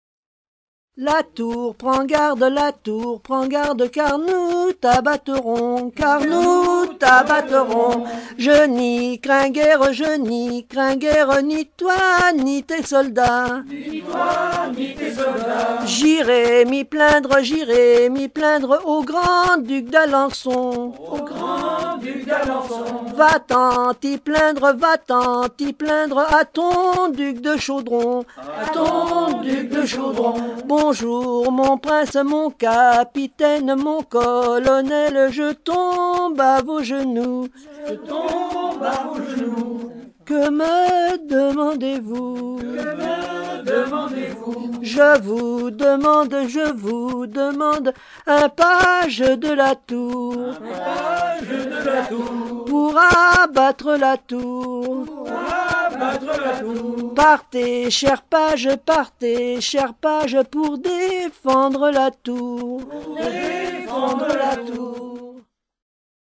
Nalliers ( Plus d'informations sur Wikipedia ) Vendée
Enfantines - rondes et jeux
rondes enfantines